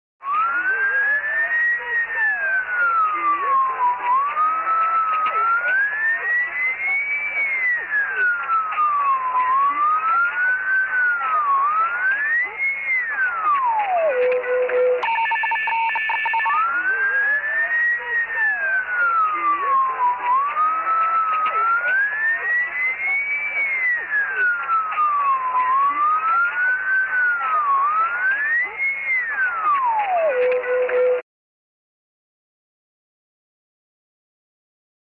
RadioTuningSWave TE040201
Radio Tuning, Tuning Short-wave Radio Through Various Frequencies, No Stations, Different Oscillating Tones with Static Background.